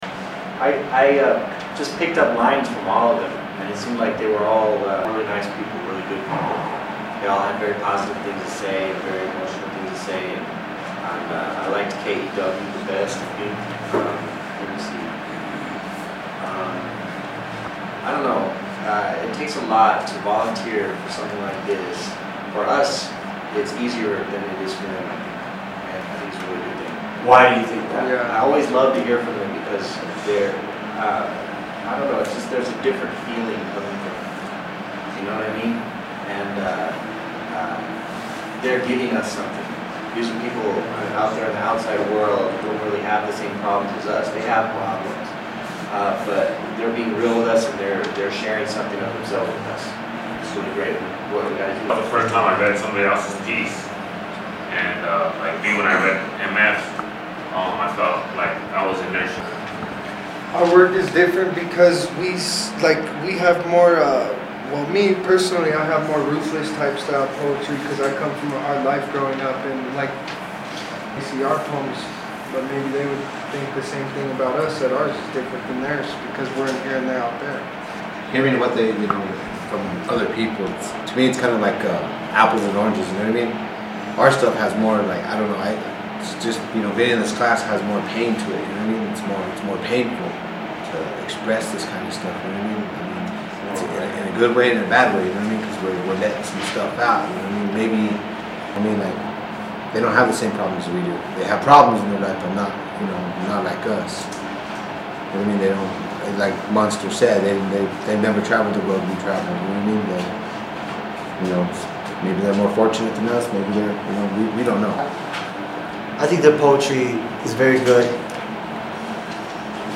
After reading and listening to what the students from Georgetown wrote in response to a prompt, MDC writers respond and discuss what the write exchange means to them.